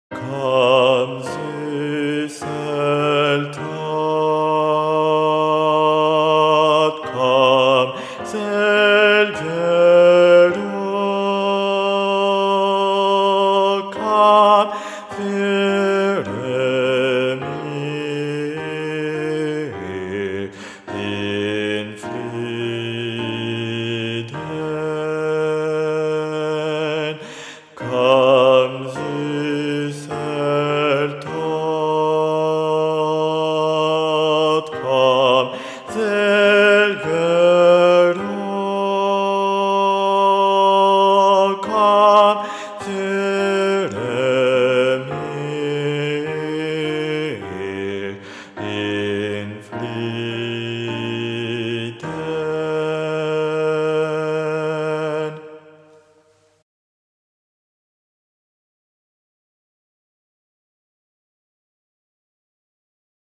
Versions chantées
Guide Voix Basses Mp 3